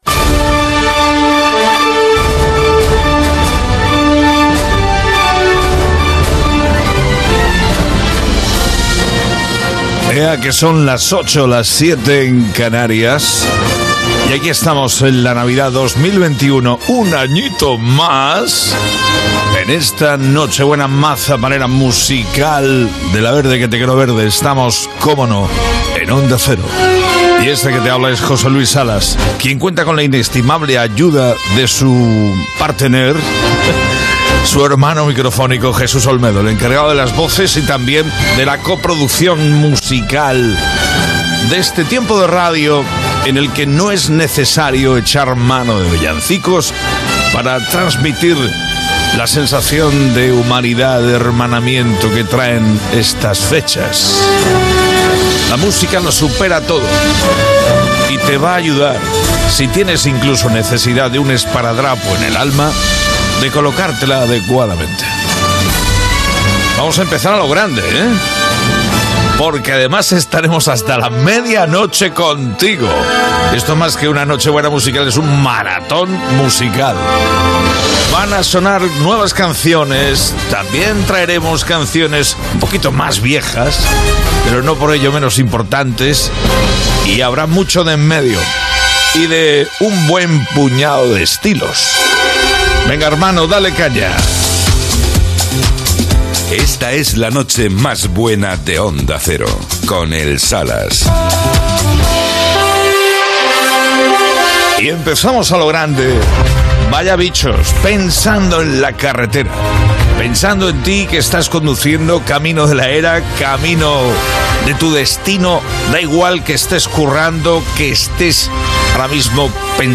Hora, presentació, indicatiu del programa i tema musical
Musical